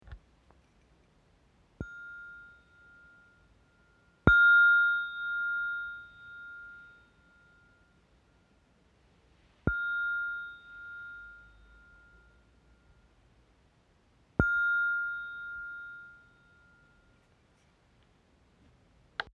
Mini bol zen • Fa 703 Hz
Ce bol, originaire d’Inde, est fabriqué à partir d’un alliage de trois métaux. Plus axé sur la sonorité que sur la vibration, il est idéal pour ajouter des accents subtils lors de bains sonores. Inspiré des bols zen japonais, il combine une esthétique pure et un son harmonieux, parfait pour enrichir vos séances de relaxation !
Note : Fa 703 Hz